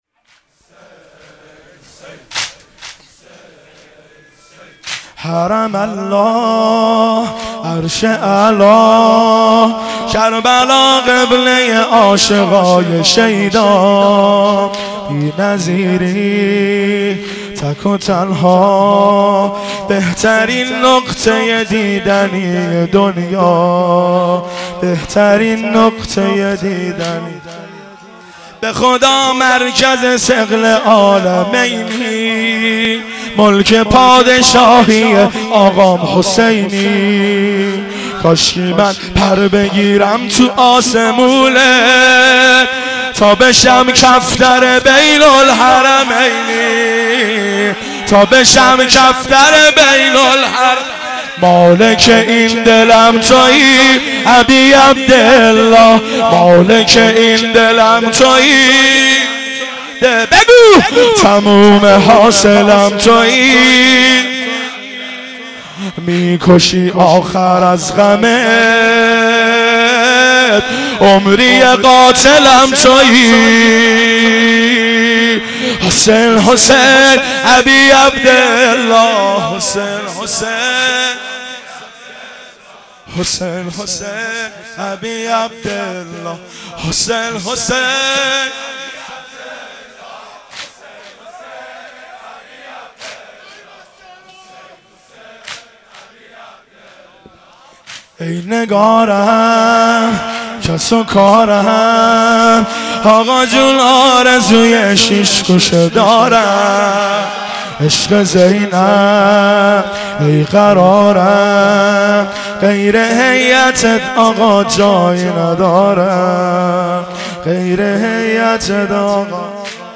شب سوم رمضان 95، حاح محمدرضا طاهری
واحد، زمینه